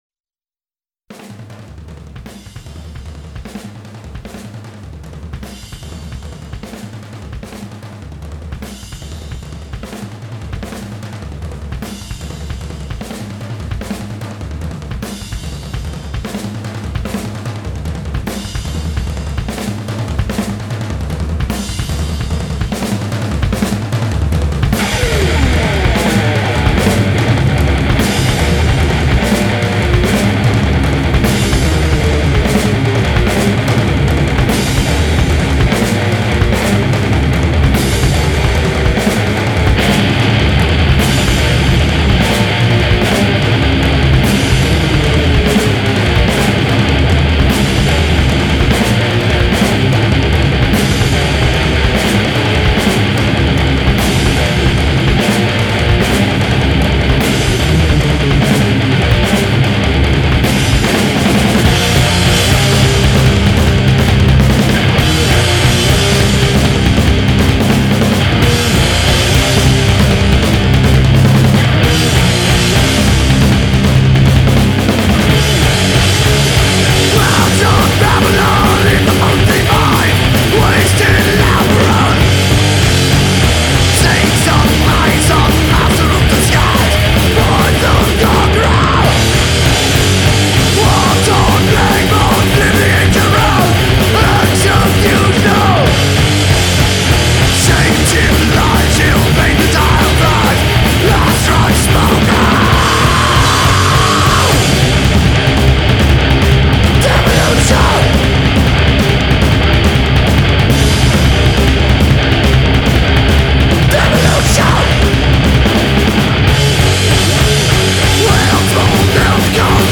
is metal for those who don’t listen to metal.
buzzsaw riffs to introspective soliloquies